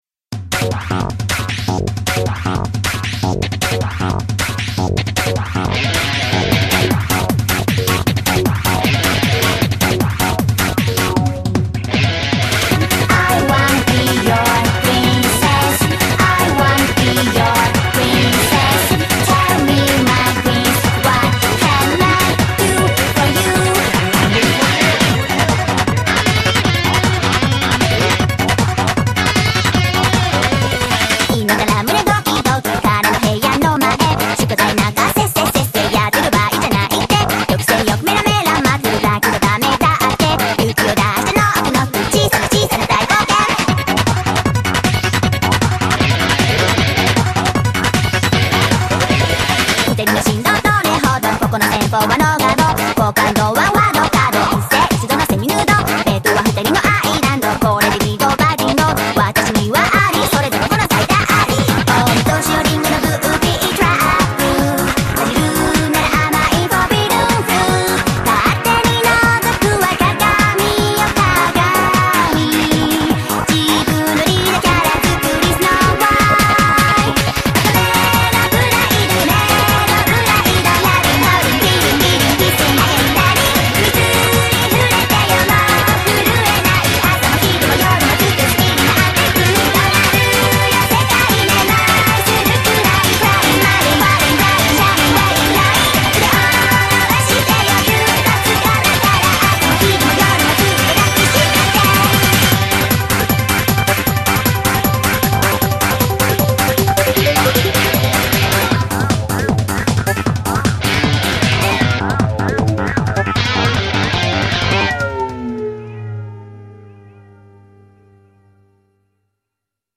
BPM155-155
Audio QualityCut From Video